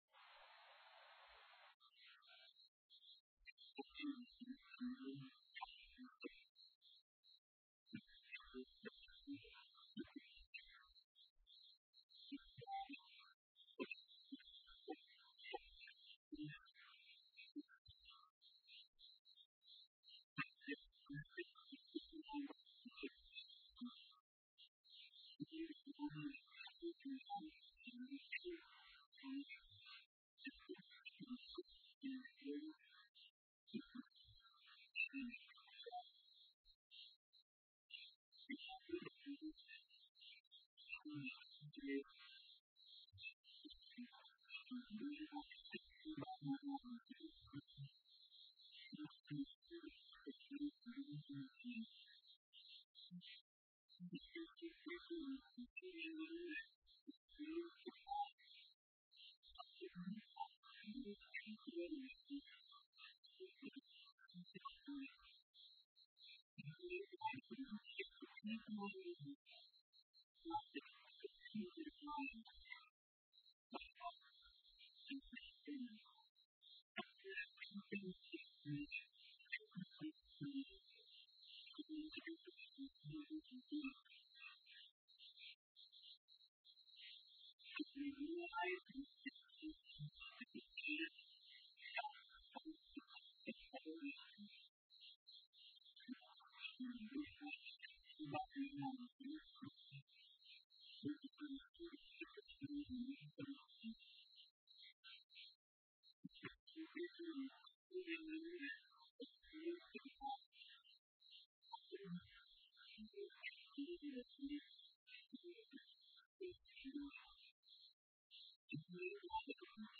In this sermon, the speaker emphasizes the importance of understanding and embracing the journey of faith. They encourage listeners to seek solutions and growth in their spiritual lives, rather than simply relying on external factors.